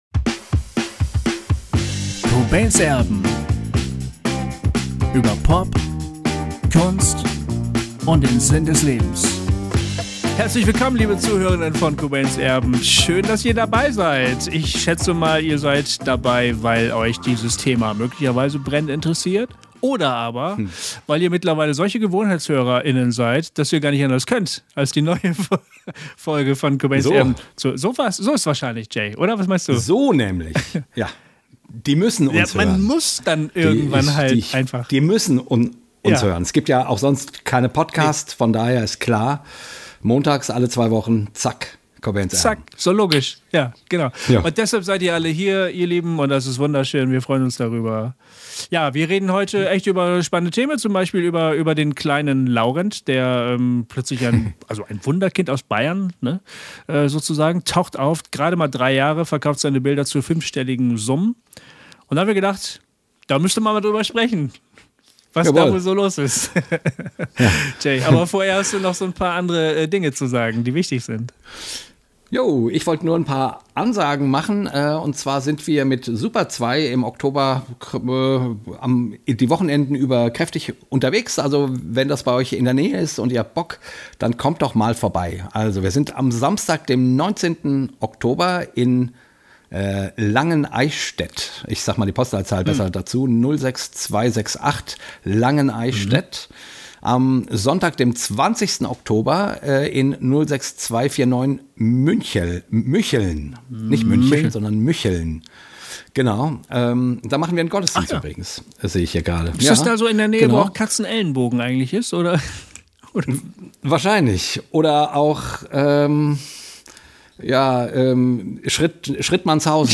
Über diese Fragen sprechen wir in unserem kurzweiligen Talk.